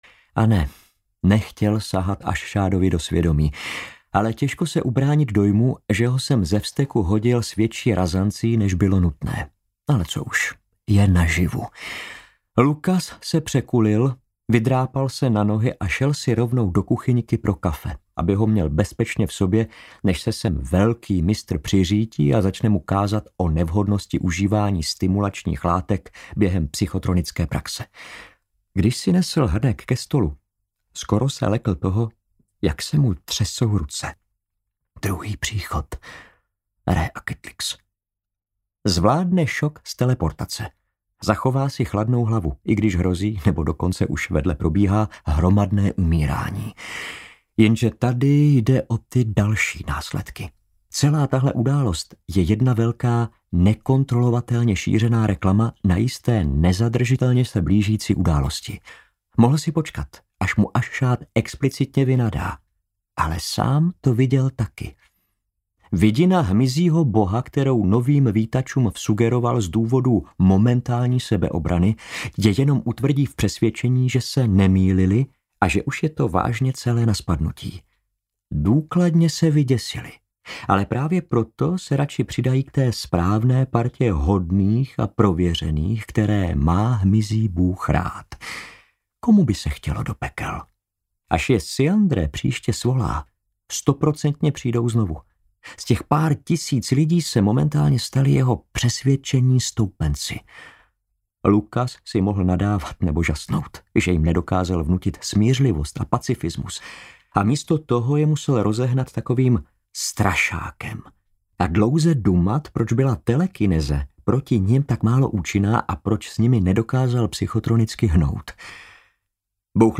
Mycelium VII: Zakázané směry audiokniha
Ukázka z knihy